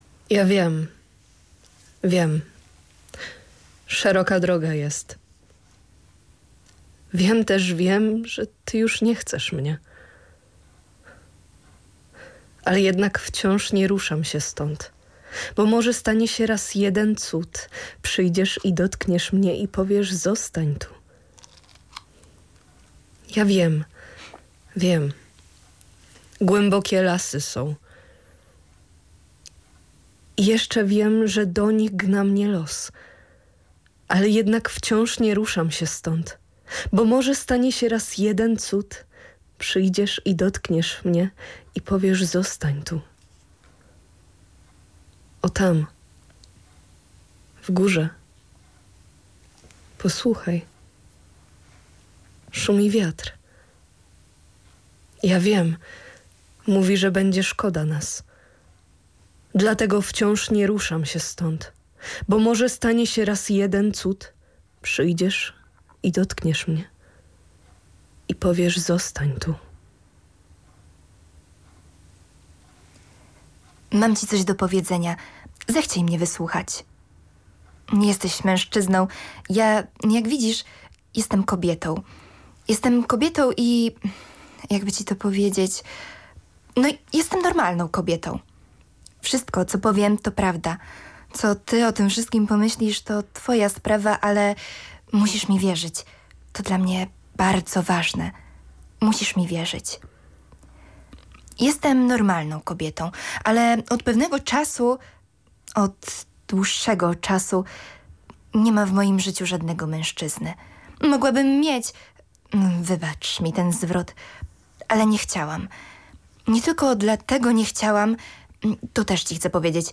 Podczas programu gościnie zaprezentowały próbkę swojego aktorskiego warsztatu.